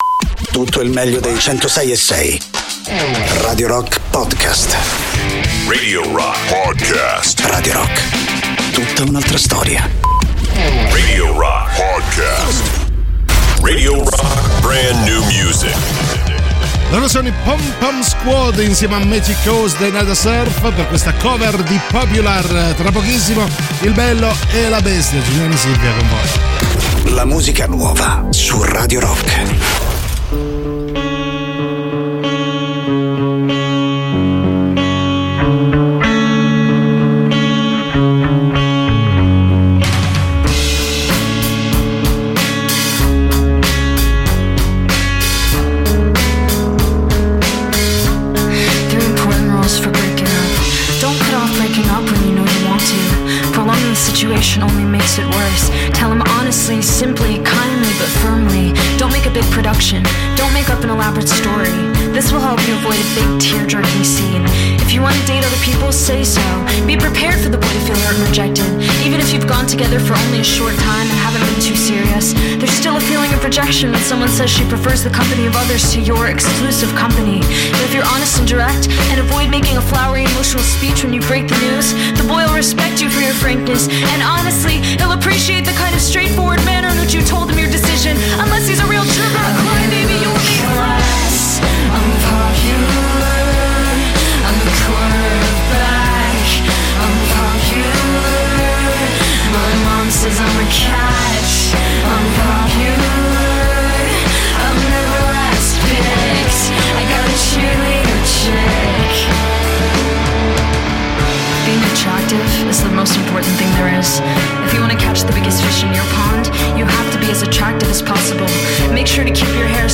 in diretta sui 106.6 di Radio Rock dal Lunedì al Venerdì dalle 13.00 alle 15.00.